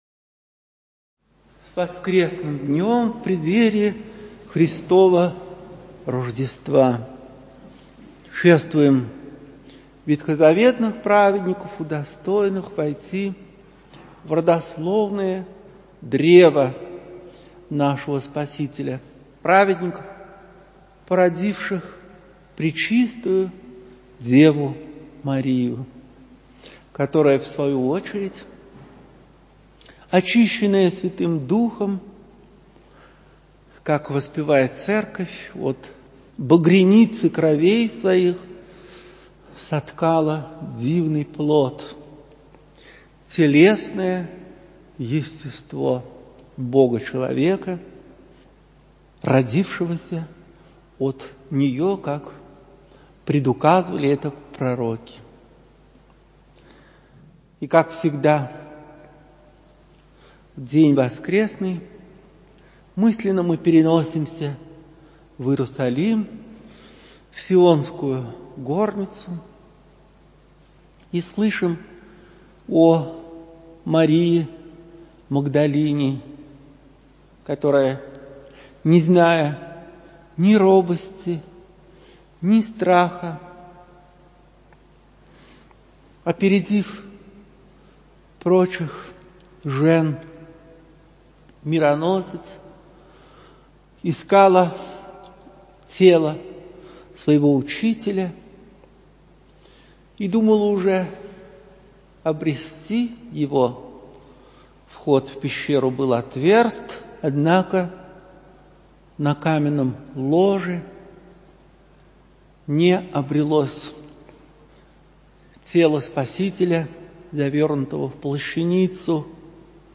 В храме Всех Святых Алексеевского ставропигиального женского монастыря, 3 января 2021.